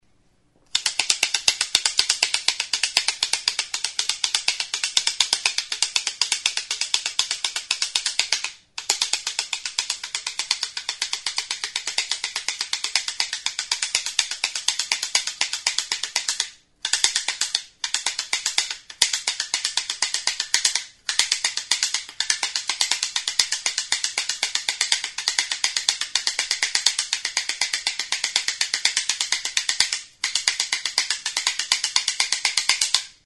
Idiophones -> Frappés -> Indirectement
Enregistré avec cet instrument de musique.
Kirtena esku tartean bi zentzuetara jiratuz, trabeska sartutako makilak alboetako hortzen kontra jotzerakoan hotsa ematen du.